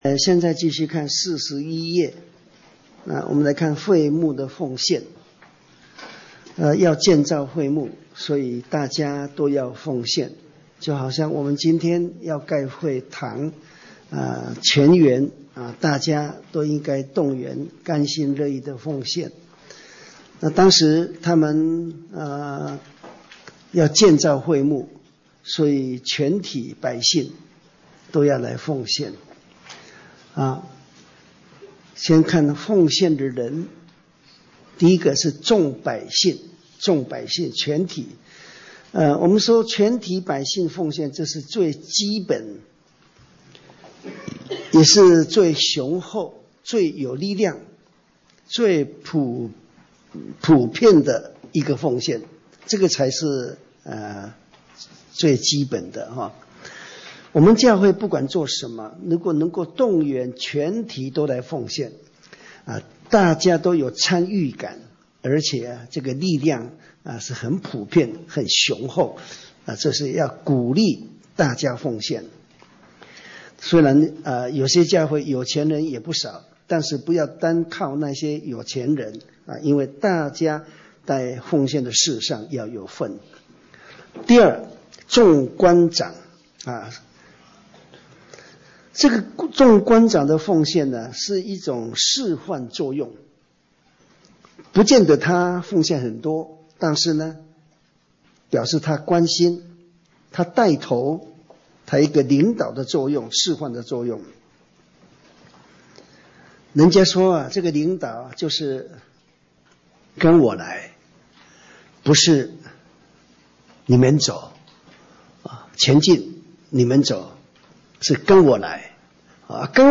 講習會
地點 台灣總會 日期 02/17/2014 檔案下載 列印本頁 分享好友 意見反應 Series more » • 出埃及記 22-1 • 出埃及記 22-2 • 出埃及記 22-3 …